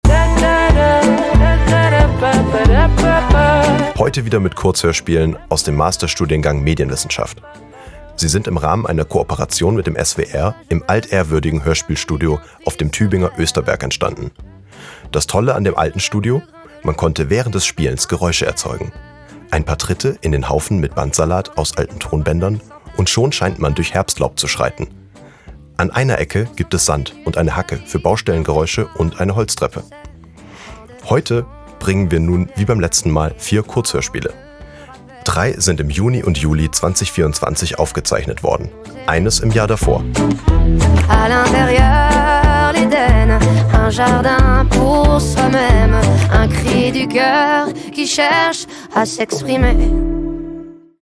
Sie sind im Rahmen einer Kooperation mit dem SWR im altehrwürdigen Hörspielstudio auf dem Tübinger Österberg entstanden. Das tolle an dem alten Studio: Man konnte während des Spielens Geräusche erzeugen: Ein paar Tritte in den Haufen mit Bandsalat aus alten Tonbändern und schon meint man durch Herbstlaub zu schreiten. An einer Ecke gab es Sand und eine Hacke für Baustellengeräusche und eine Holztreppe.